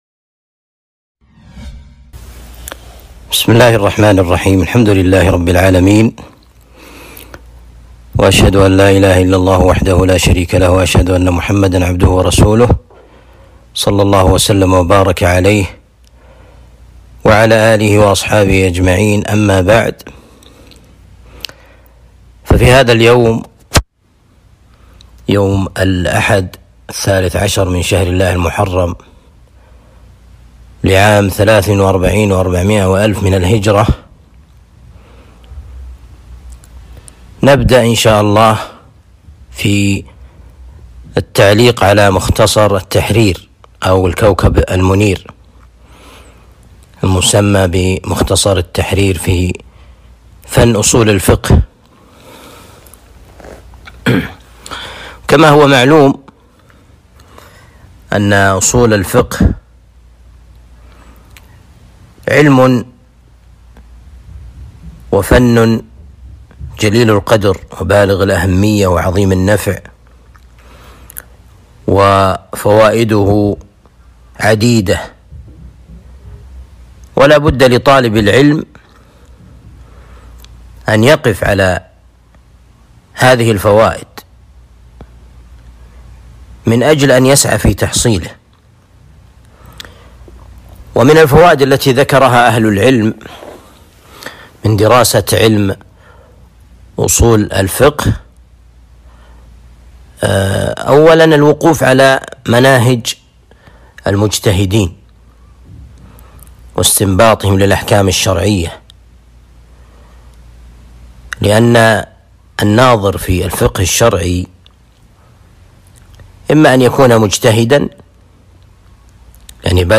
التعليق على كتاب مختصر التحرير في أصول الفقه الدروس التعليق على كتاب مختصر التحرير في أصول الفقه المقطع 1.